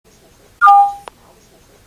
Potřebu dobít baterii telefon včas
ohlašuje tímto zvukem.